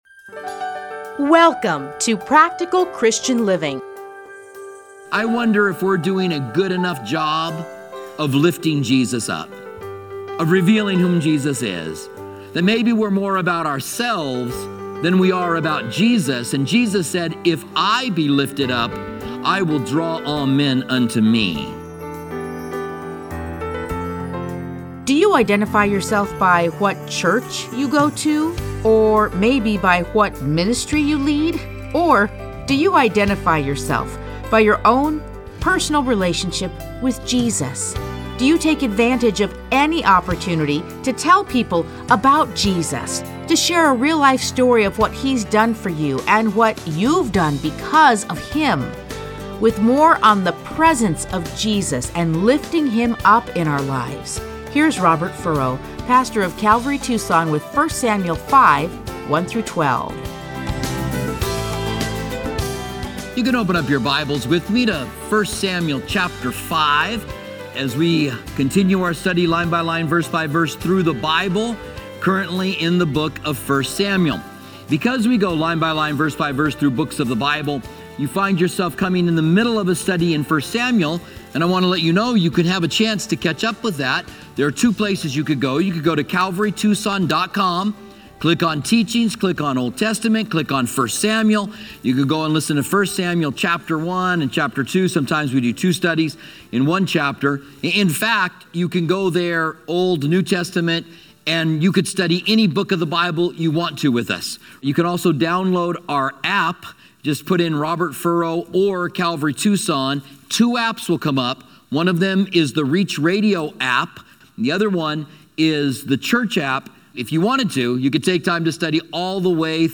Listen to a teaching from 1 Samuel 5:1-12.